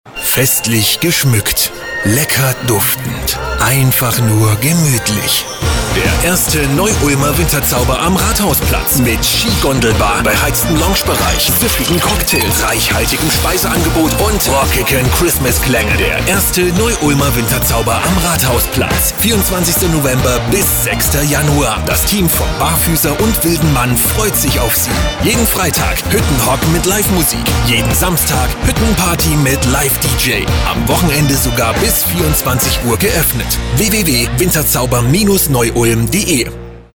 Sprechprobe: Werbung (Muttersprache):
german voice over artist